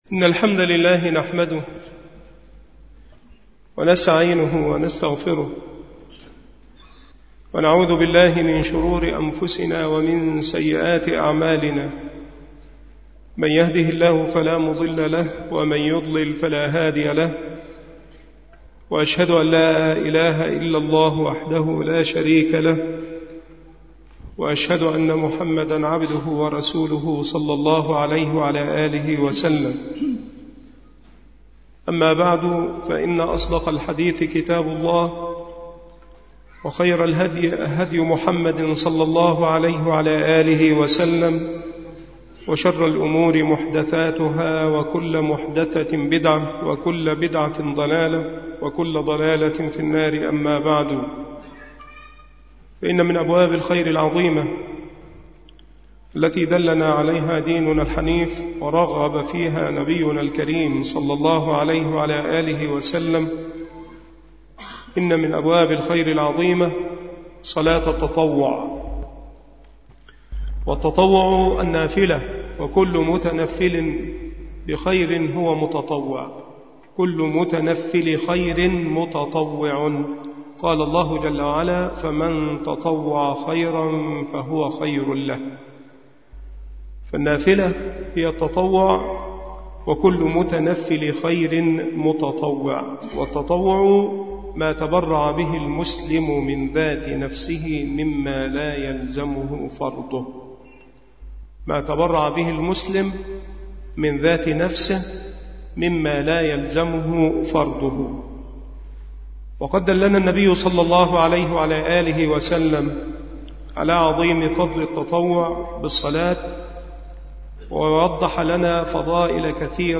مكان إلقاء هذه المحاضرة بمسجد أولاد غانم بمدينة منوف - محافظة المنوفية - مصر